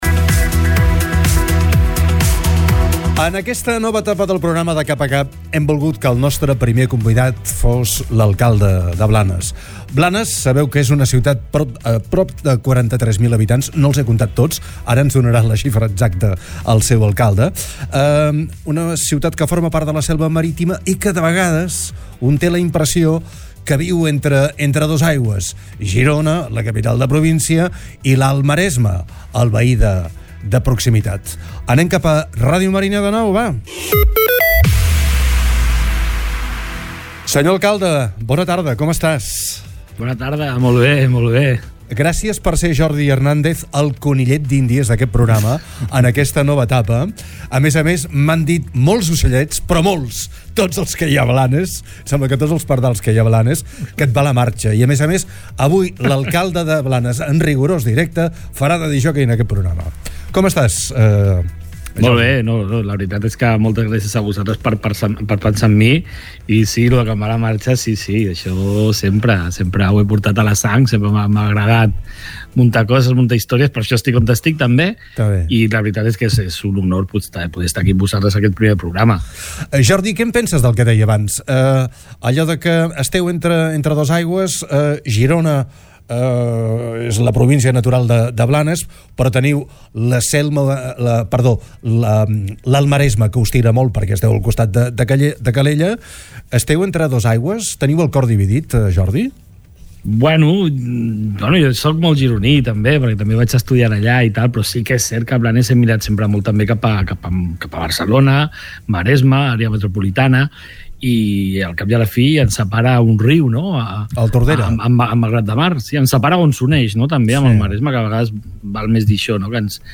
Des dels estudis de ràdio Marina, Jordi Hernandez ha repassat l’actualitat del municipi i, fins i tot, s’ha atrevit a fer de DJ.